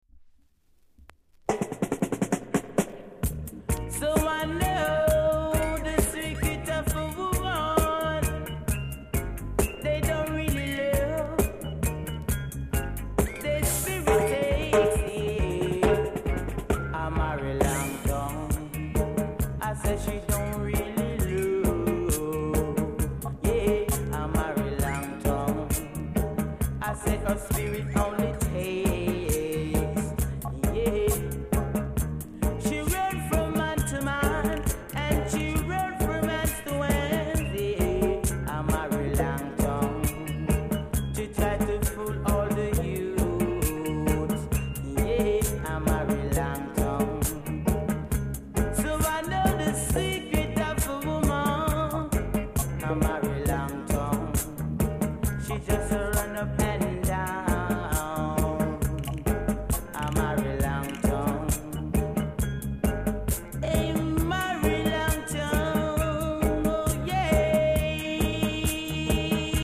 ※小さなチリノイズが少しあります。
コメント STEPPER!!GREEN WAX